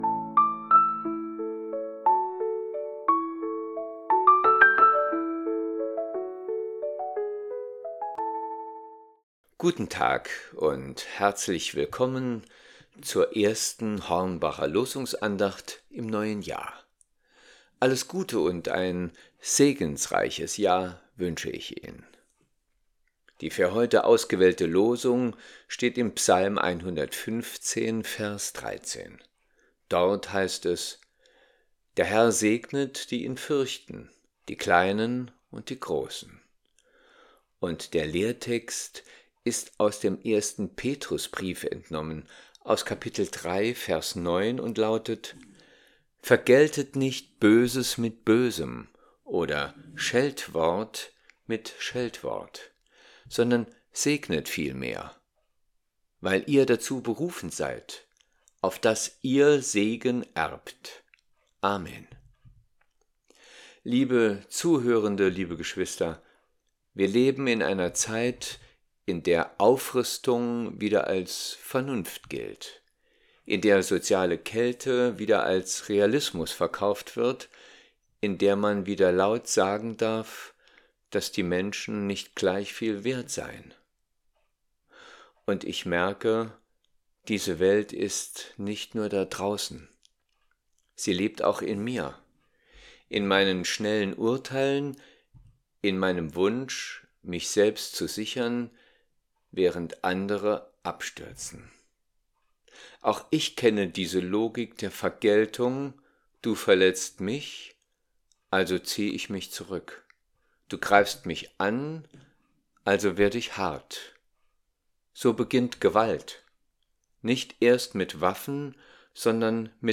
Losungsandacht für Freitag, 02.01.2026